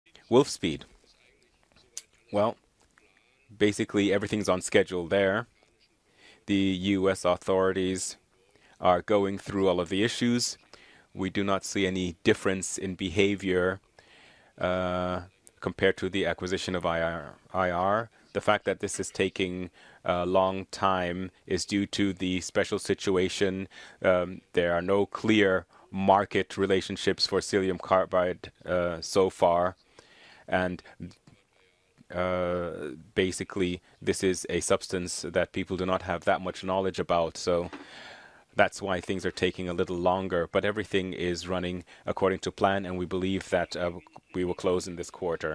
During a call with the press following Infineon's Q1 FY2017 earnings release, CEO Reinhard Ploss responded to a question about the status of the Wolfspeed acquisition. You'll hear the voice of a translator, as Ploss was presumably answering in German.